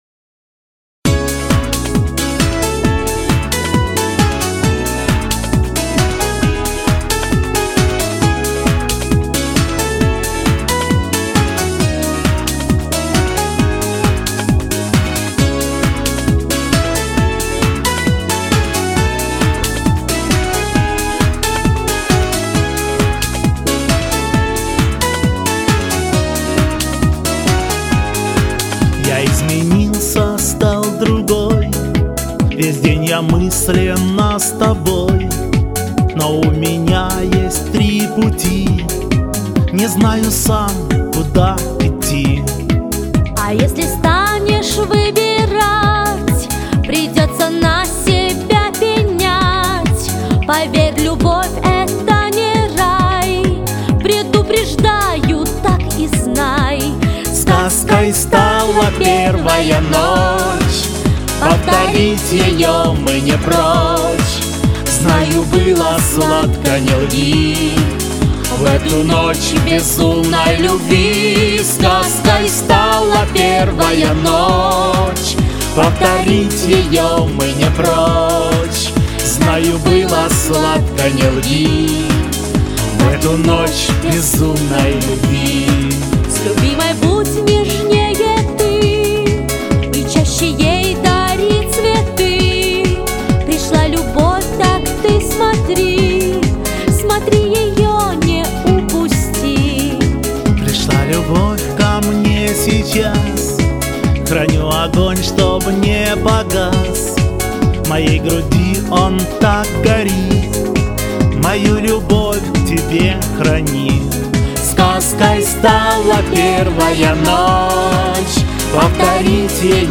Рубрика: Поезія, Авторська пісня
Исполнение трогает душу.